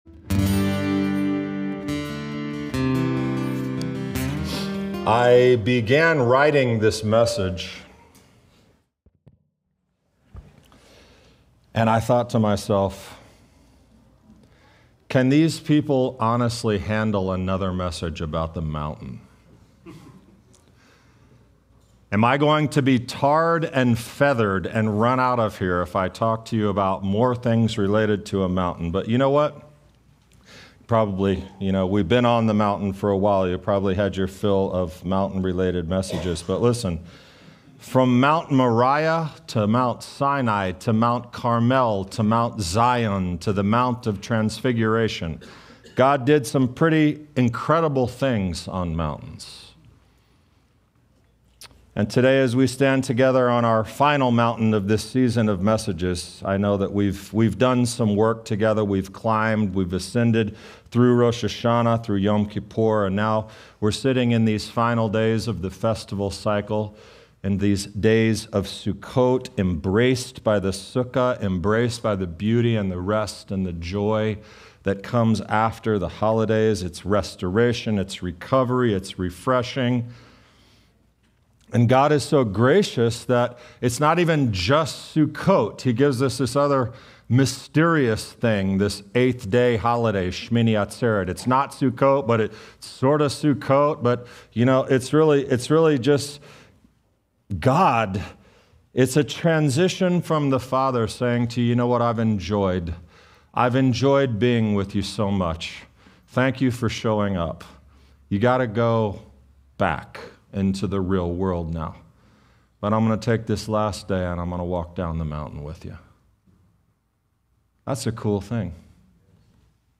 This message will challenge how you live once the festival lights fade.